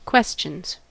questions: Wikimedia Commons US English Pronunciations
En-us-questions.WAV